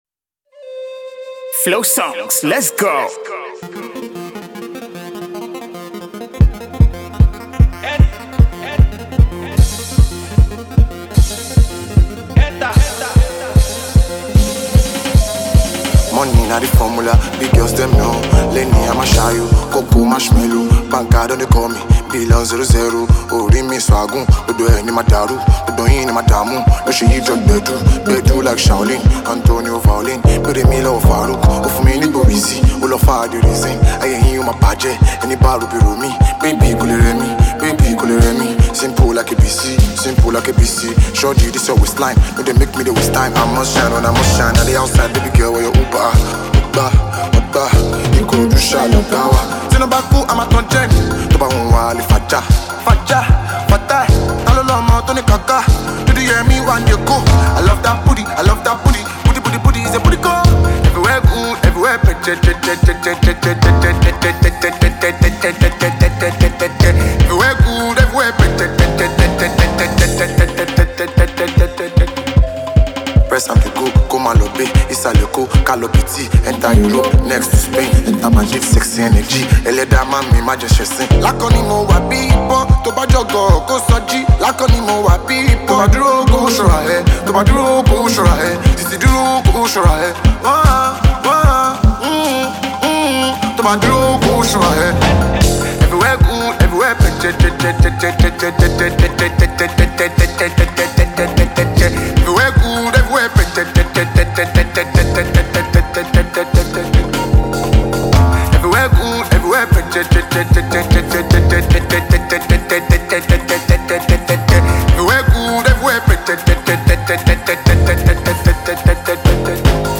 It is a catchy and lively melody.
AFROPOP and Afrobeat